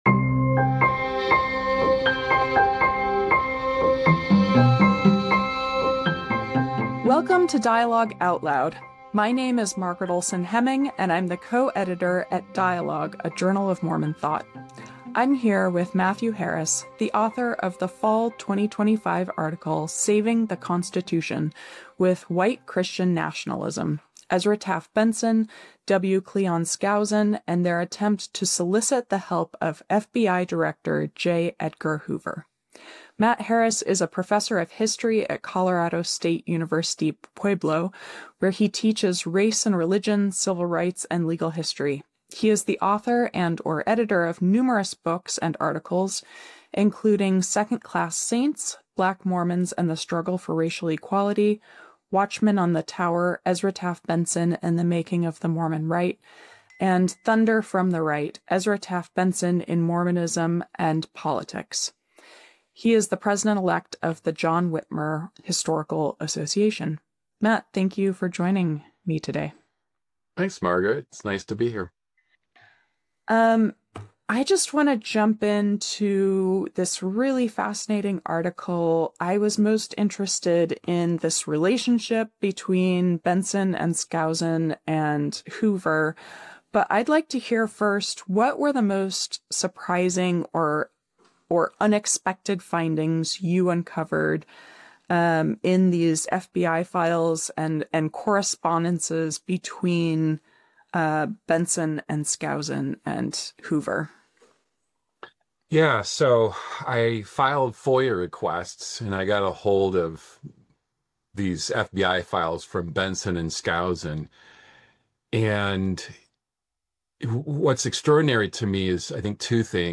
Mormonism, Anticommunism, & the Politics of Christian Nationalism: A Conversation w